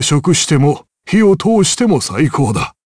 Dakaris-Vox-Halloween_Skill1_jp.wav